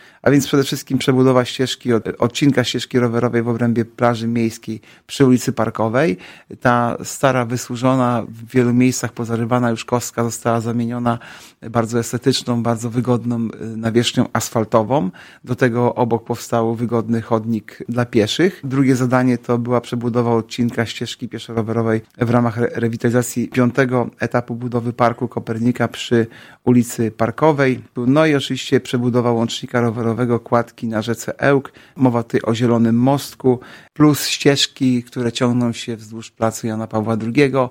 – Prace podzielone zostały na trzy etapy – mówi Artur Urbański, zastępca prezydenta Ełku.